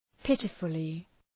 Προφορά
{‘pıtıfəlı}
pitifully.mp3